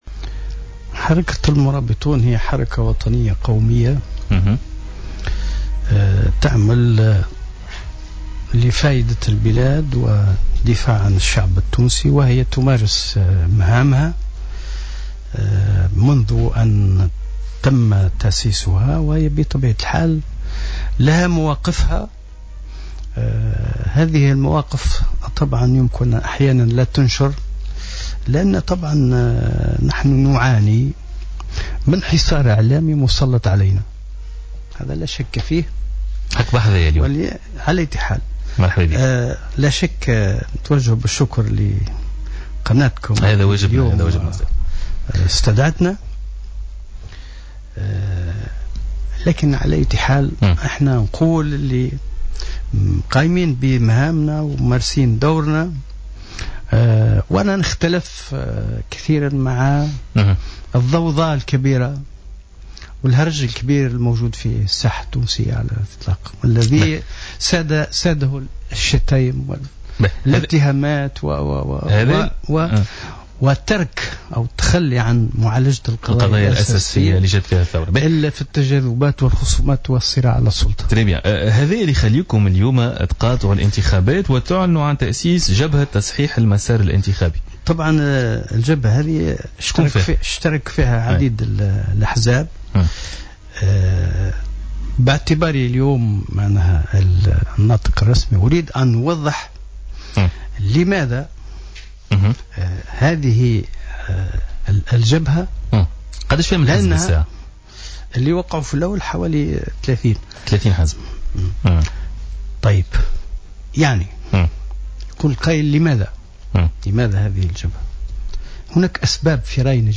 أكد رئيس حركة المرابطون والناطق الرسمي باسم جبهة تصحيح المسار الانتخابي،البشير الصيد اليوم خلال مداخلة له في برنامج "بوليتيكا" أن جبهة تصحيح المسار الانتخابي ستقاطع الانتخابات التشريعية والرئاسية القادمة.